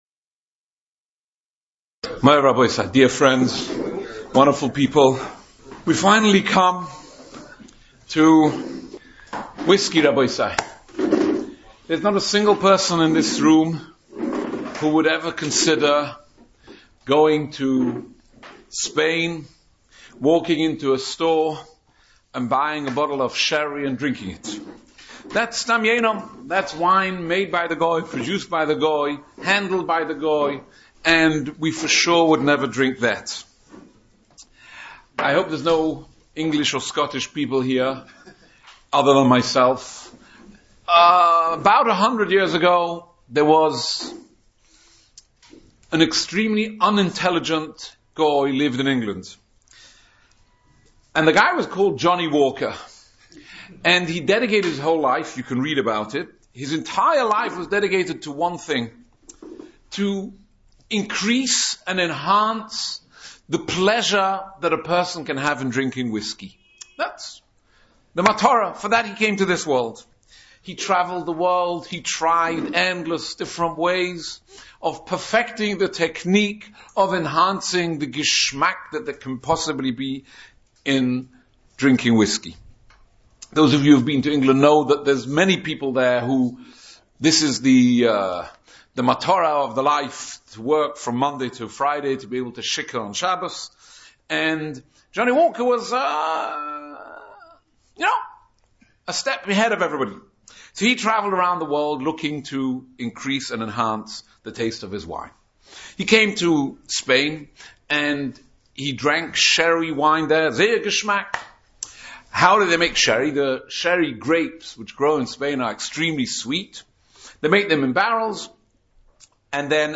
Shiurim
In Day 3, Yarchei Kallah - 2024